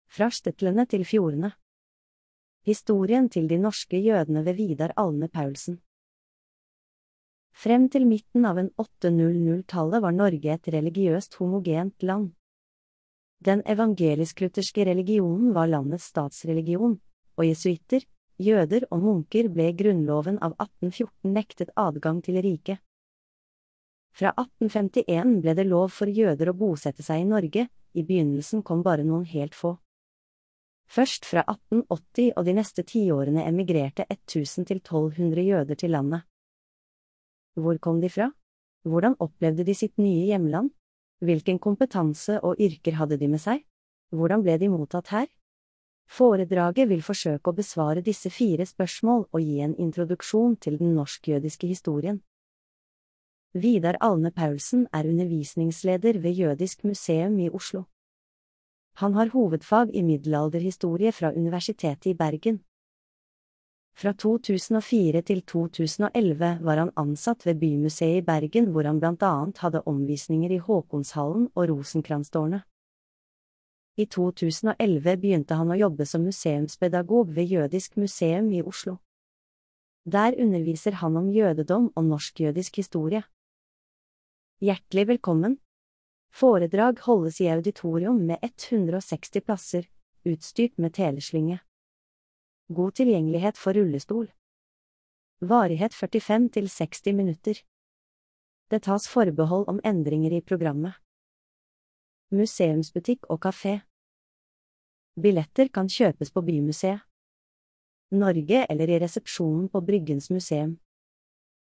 Populærvitenskapelige foredrag. Bryggens Museum.